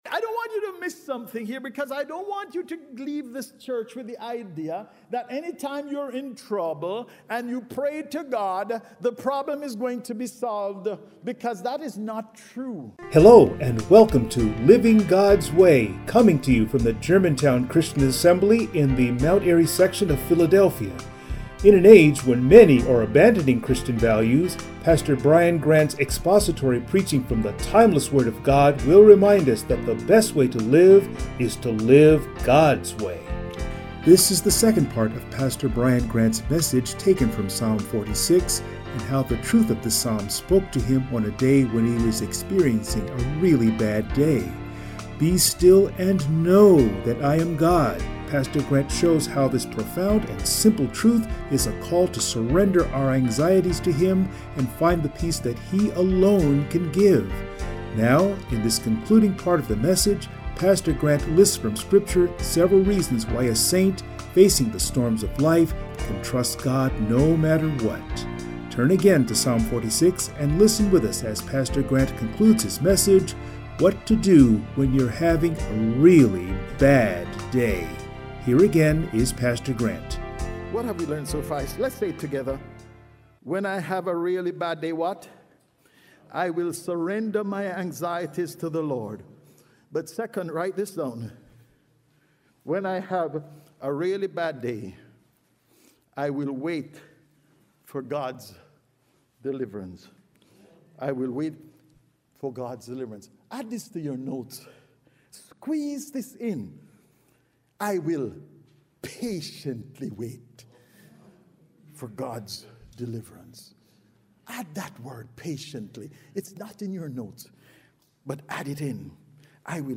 Psalm 46 Service Type: Sunday Morning “Be still